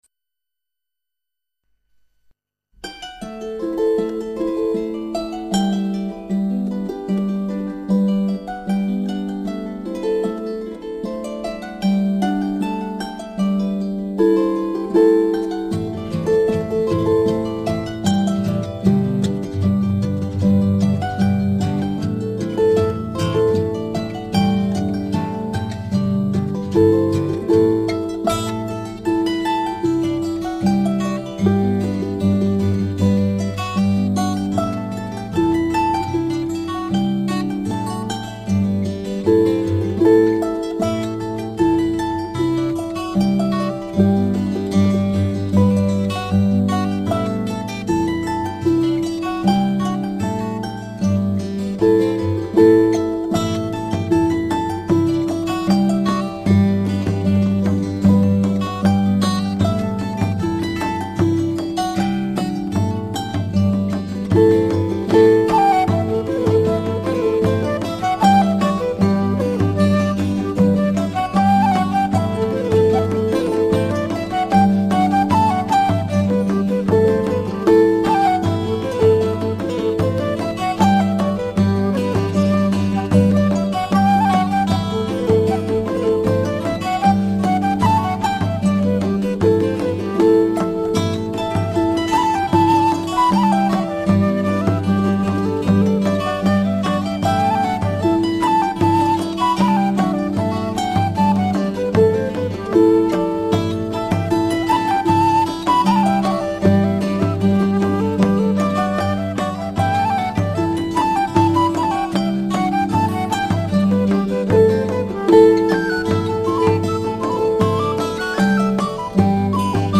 0179-竖琴名曲燕尾轮.mp3